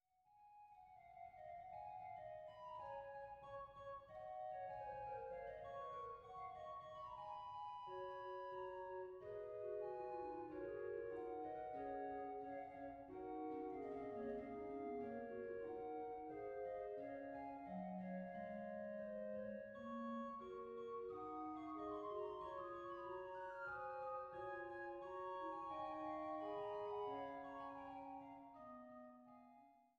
Hildebrandt-Orgel in Langhennersdorf